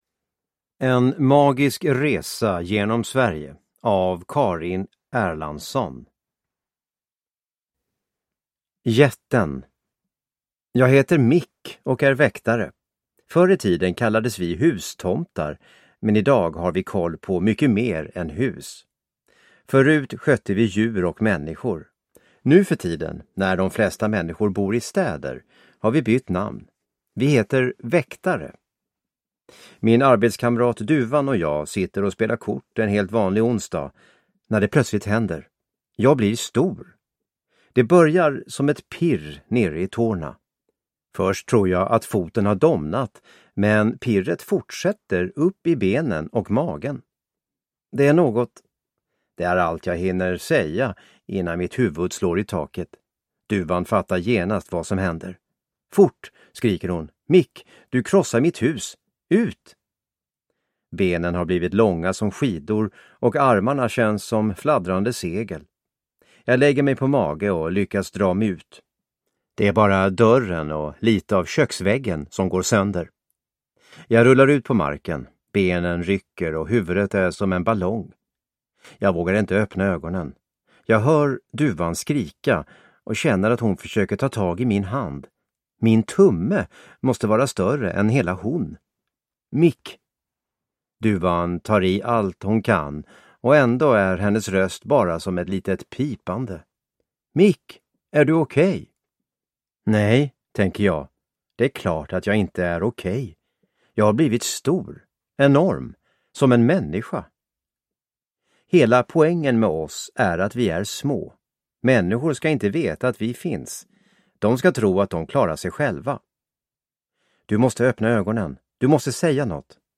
En magisk resa genom Sverige – Ljudbok